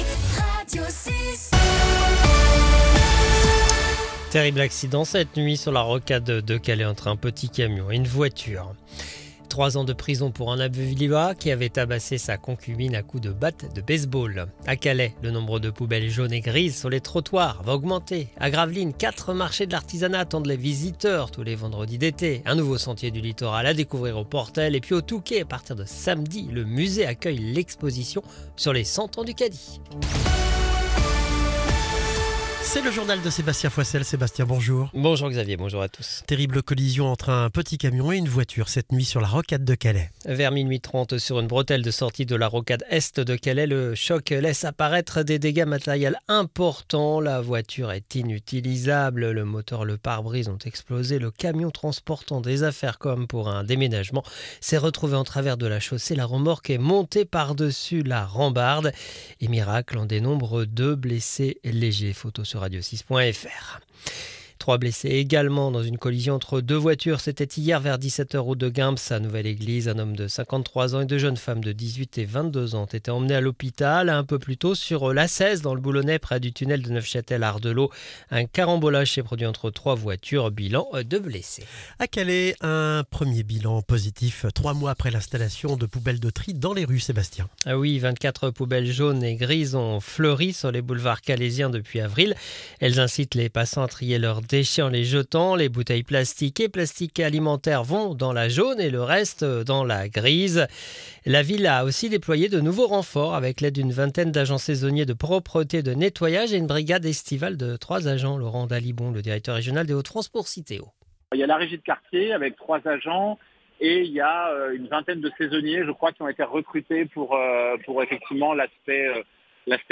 Le journal du Jeudi 3 juillet 2025